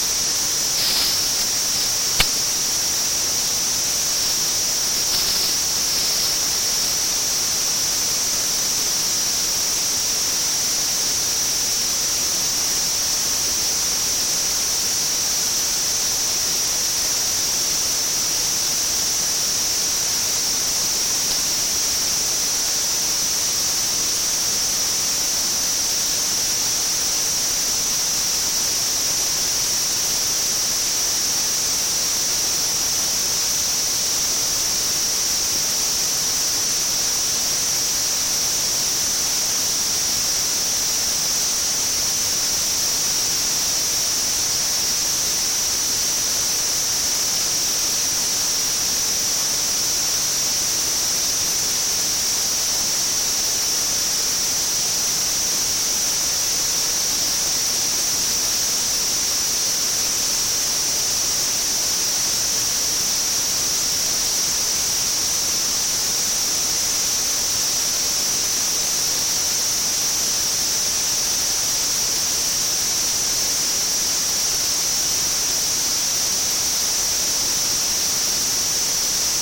This ultrasound background signal comes in at 60 Khz.  It can give you an impression that it is from the mains power supply, in some countries it is 50 hz and others at 60 hz. background check got some chatter But then it can also come in at about 30 Hz and scattered. background check at 30 Khz mp3
background-check-got-some-chatter.mp3